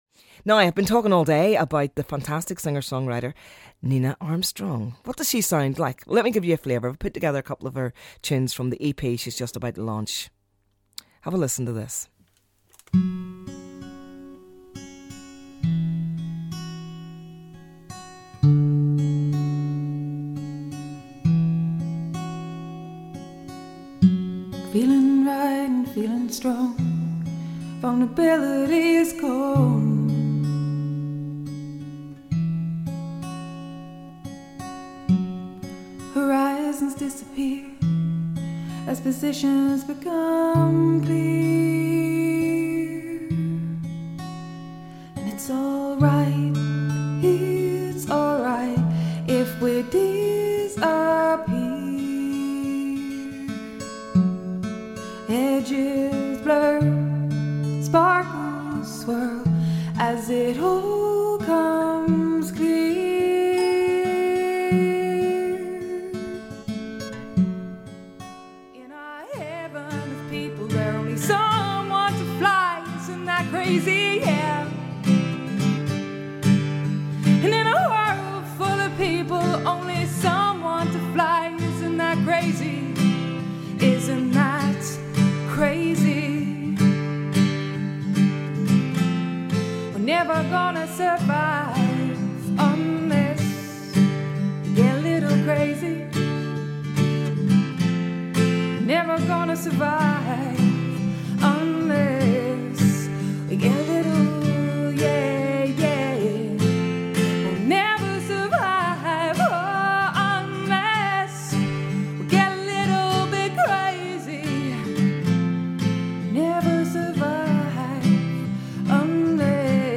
Interview and Song!